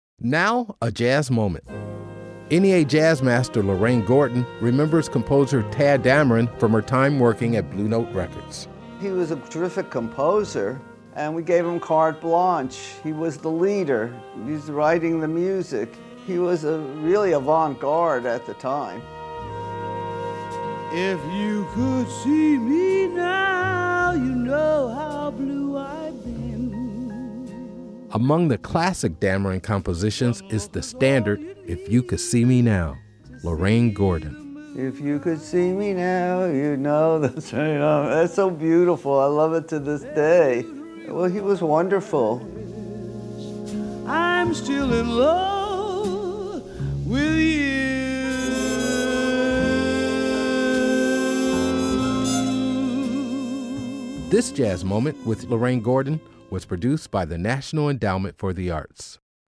Excerpt of "If You Could See Me Now” composed by Tadd Dameron and performed by Sarah Vaughan & The Count Basie Orchestra from Send in the Clowns, used by permission of Concord Records